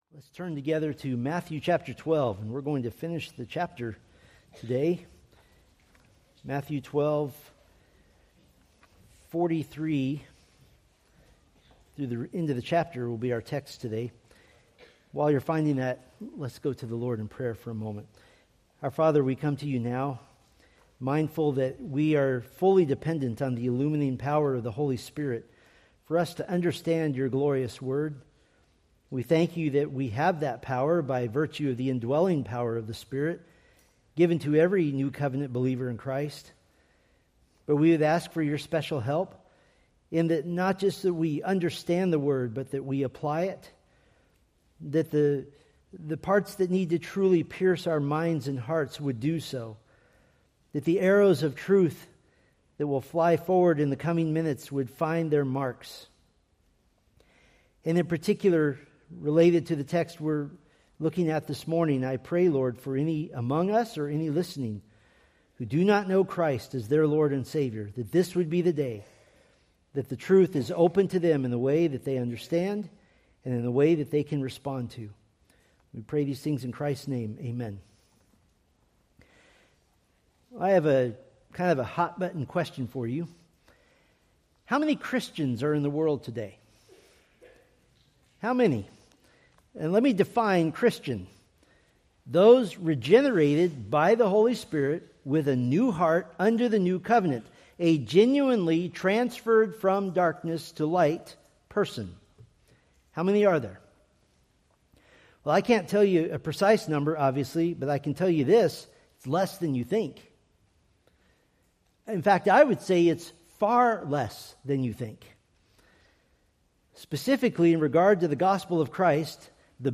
Preached January 25, 2026 from Matthew 12:43-50